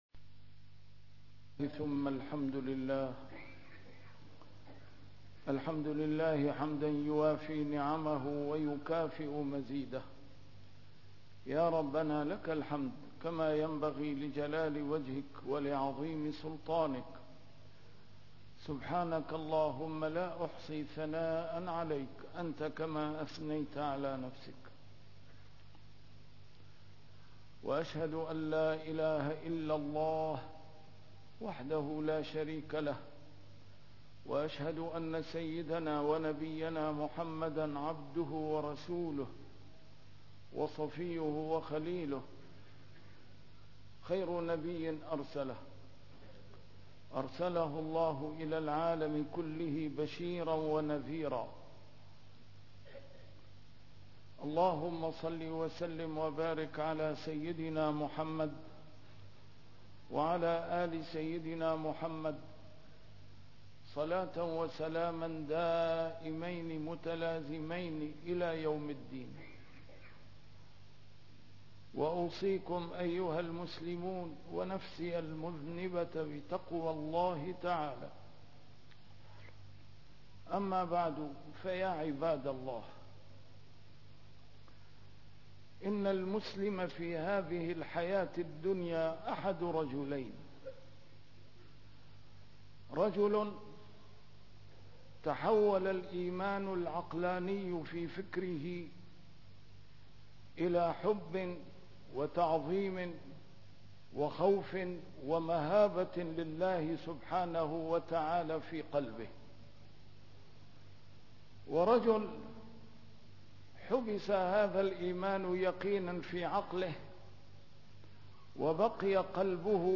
A MARTYR SCHOLAR: IMAM MUHAMMAD SAEED RAMADAN AL-BOUTI - الخطب - منطق الاحتياط لدين الله